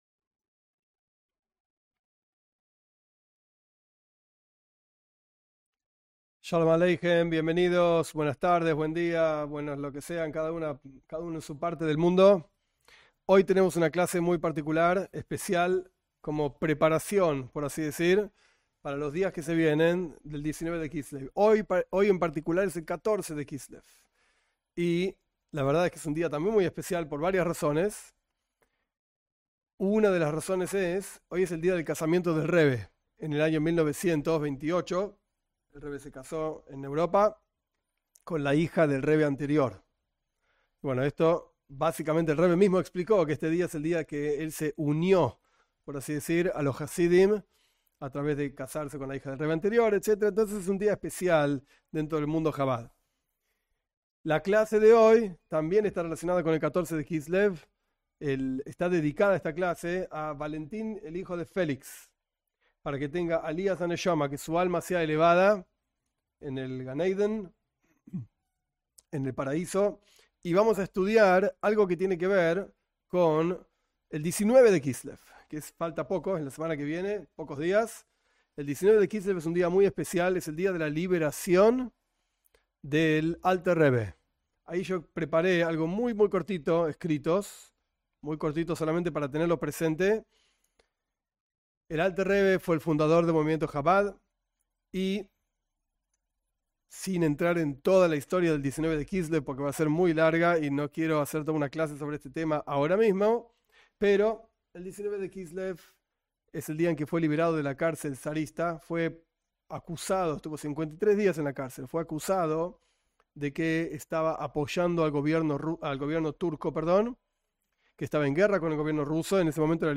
En esta clase estudiaremos una charla del Rebe del año 1984/1984 sobre el 19 de Kislev. Esa fecha del calendario hebreo marca el día de la liberación del Alter Rebe, Rabí Shneur Zalman de Liadí, fundador del movivmiento Jabad, de la cárcel en la Rusia imperial. El Rebe explica algunas partes de una carta que escribió el Alter Rebe mismo sobre la importancia de este día y hace énfasis en la relación con los no Judíos.